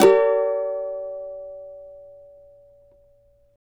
CAVA G#MN  U.wav